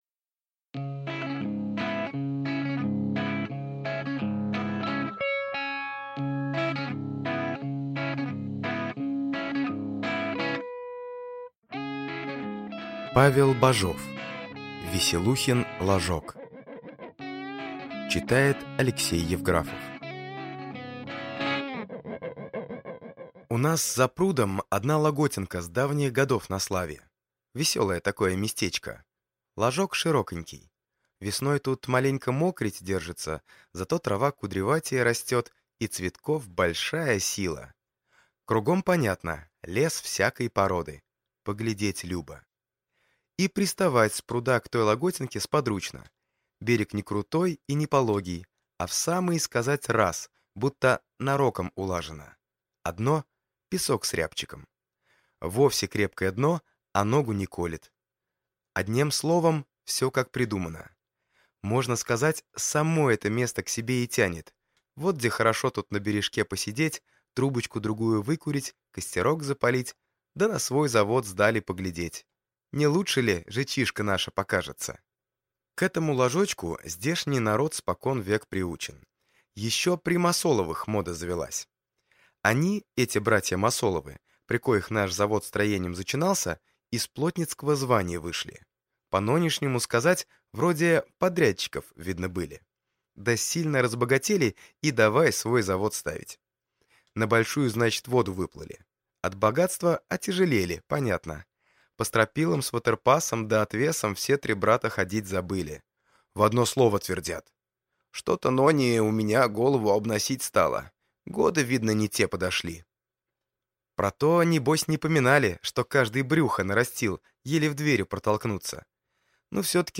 Аудиокнига Веселухин ложок | Библиотека аудиокниг